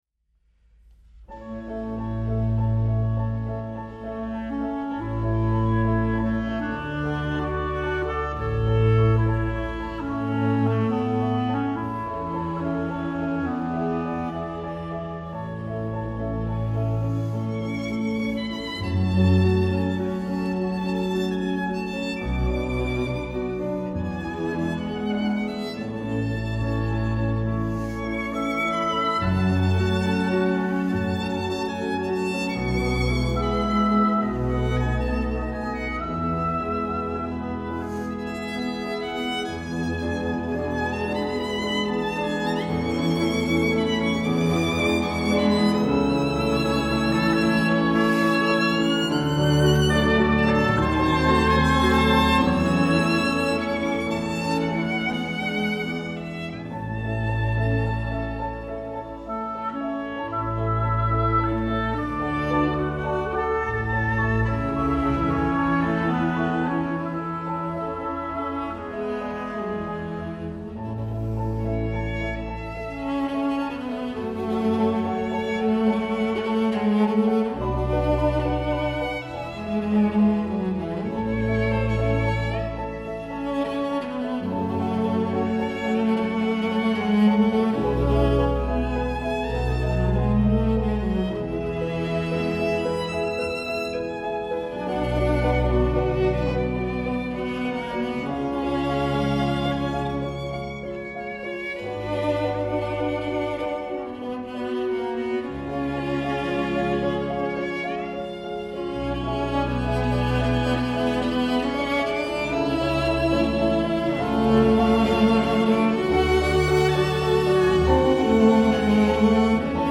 Klarinette
Saxofon
Klavier
Salonorchester